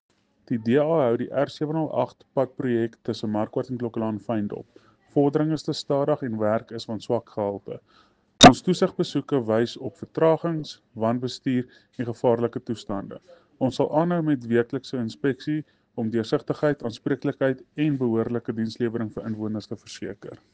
Afrikaans soundbites by Cllr Jose Coetzee and Sesotho soundbite by Jafta Mokoena MPL.